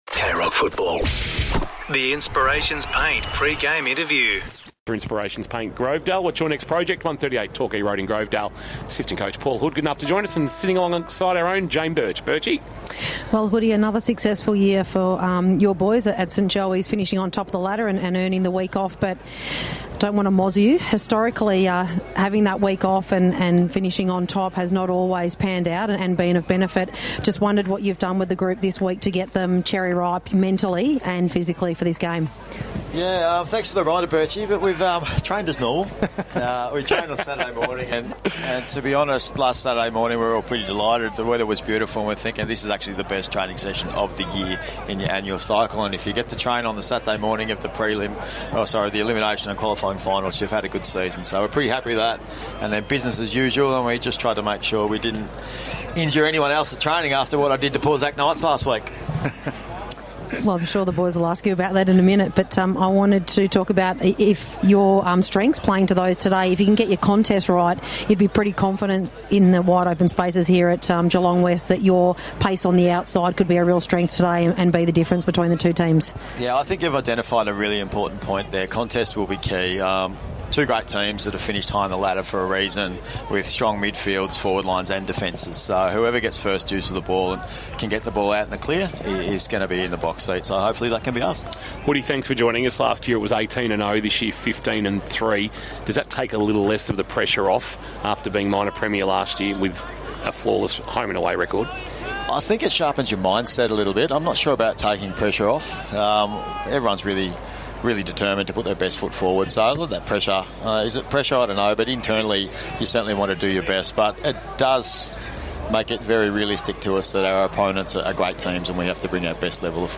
2025 - GFNL - Second Semi-Final - St Joseph's vs. Colac - Pre-match interview